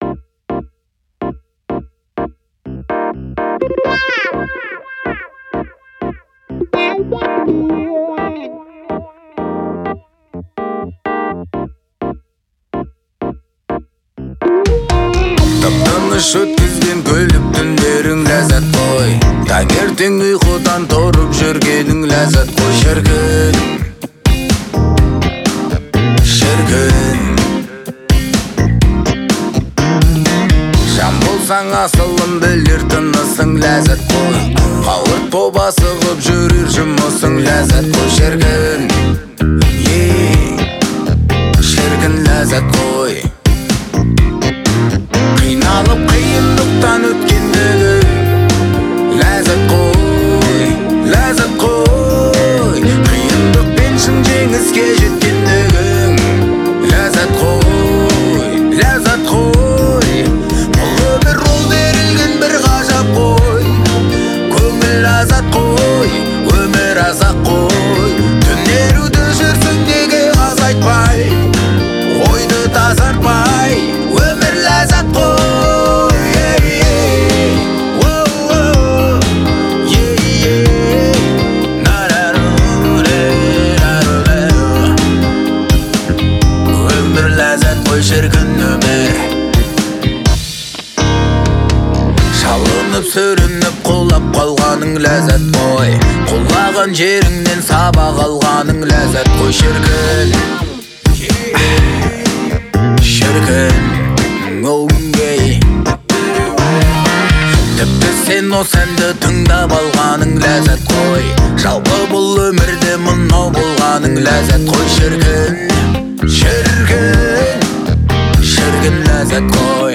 мелодичным вокалом и искренними эмоциями